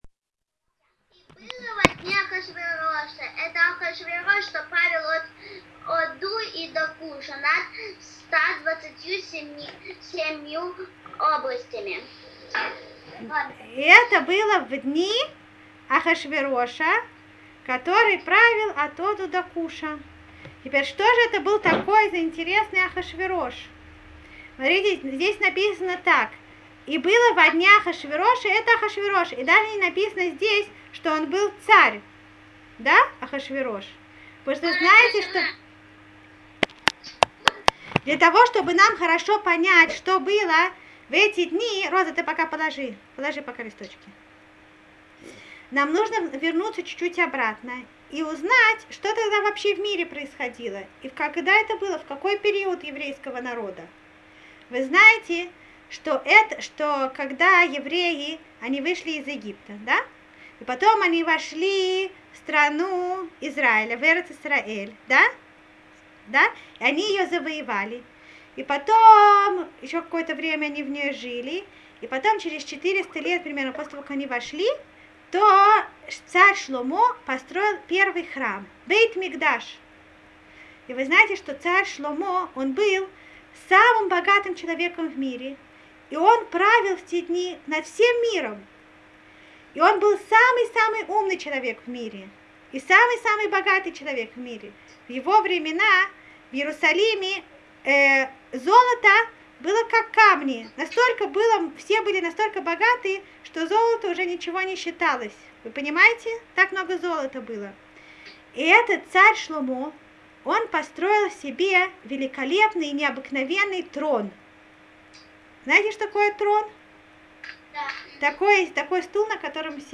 Мегилат Эстер 1 – Аудиоурок на основе мидрашей и ТаНаХа. Вы узнаете о том, как жили евреи в чужой земле после разрушения Первого Храма.